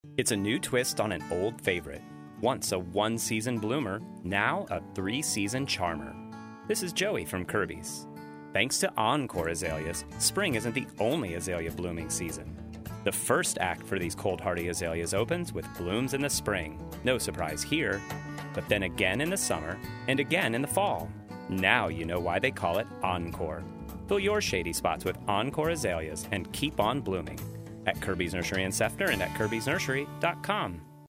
Spring 2019 Radio Ads